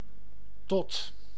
Ääntäminen
IPA: [ˈtot] Tuntematon aksentti: IPA: /tɔt/